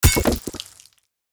axe-mining-ore-9.ogg